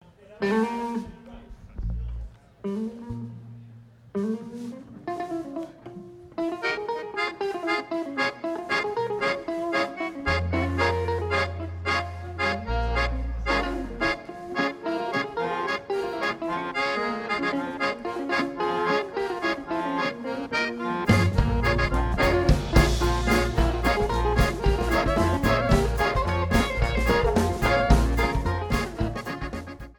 Folk & Traditional
World Rhythms